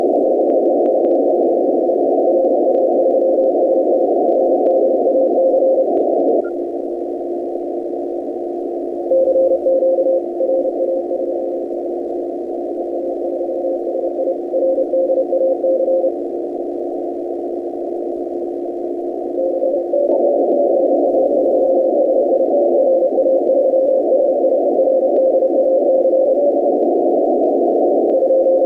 > Noise reduction
Name: noise-reduction.mp3